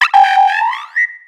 Cri de Kungfouine dans Pokémon X et Y.